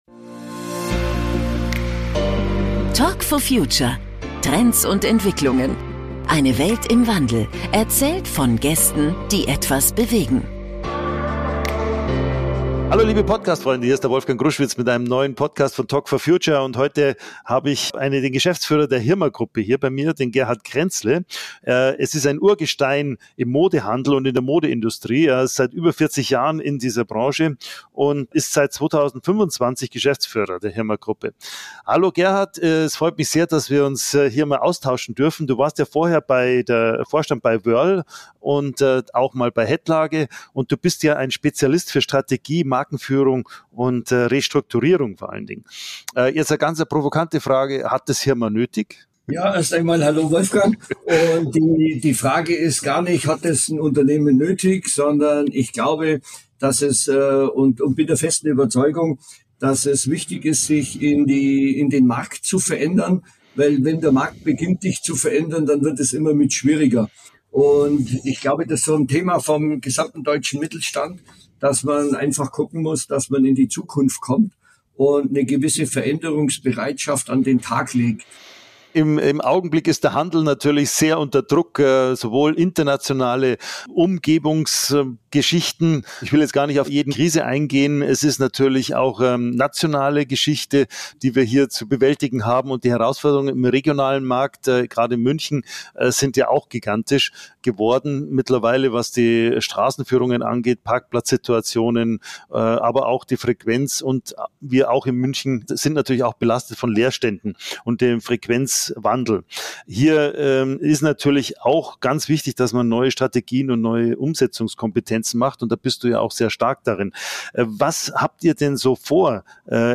Ein inspirierendes Gespräch über Veränderungsbereitschaft, Kundenerwartungen und die Werte eines traditionsreichen Unternehmens.